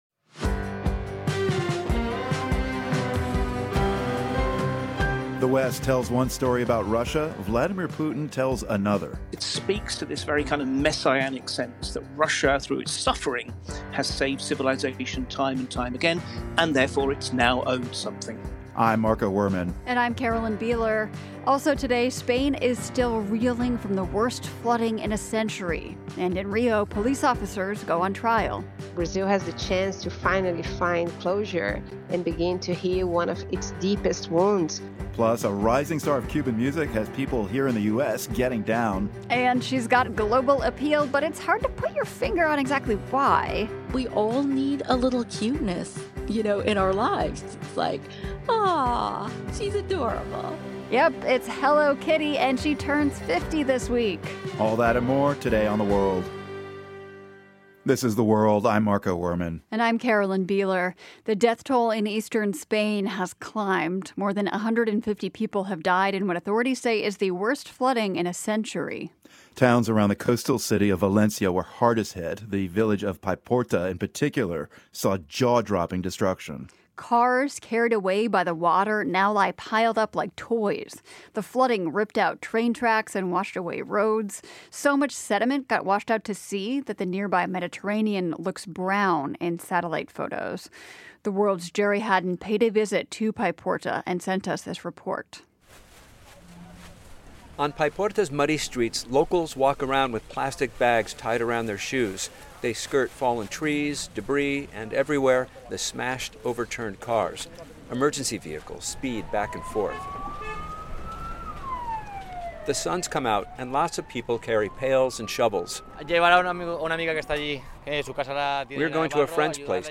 Plus, a talk with author and Russia scholar Mark Galeotti, whose new book chronicles Russia's vivid military history.